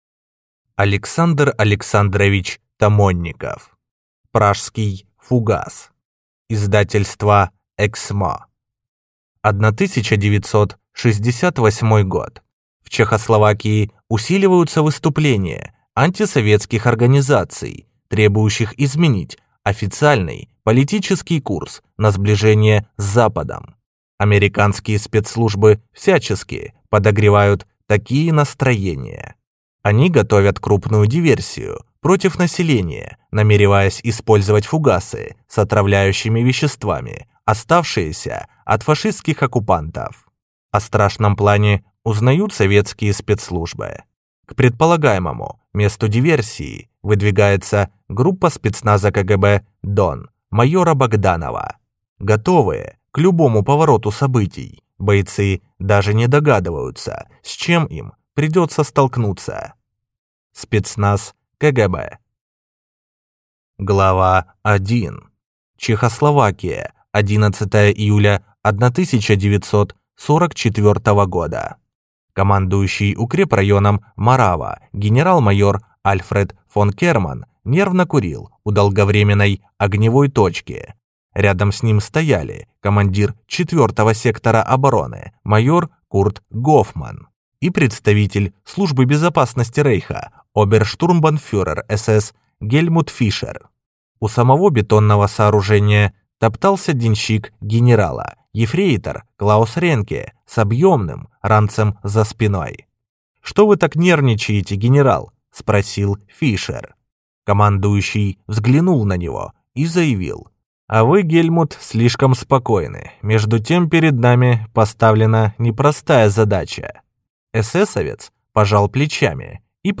Аудиокнига Пражский фугас | Библиотека аудиокниг